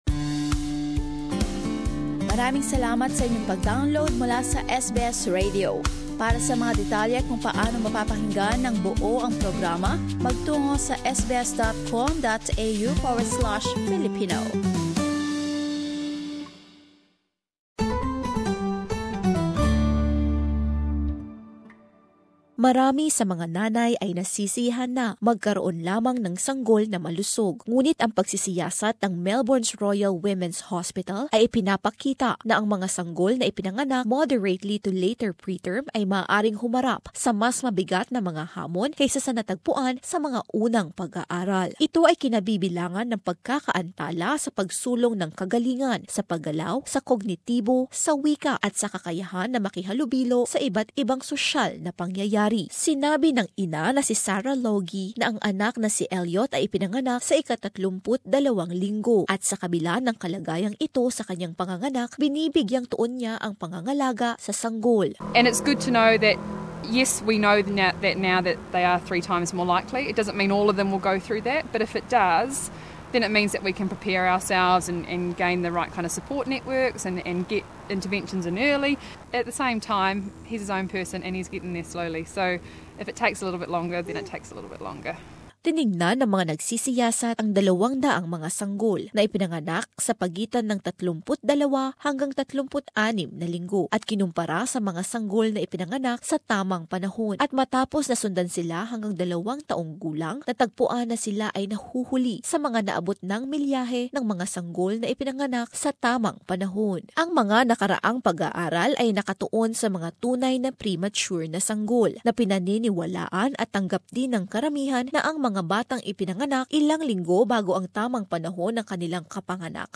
Here is a report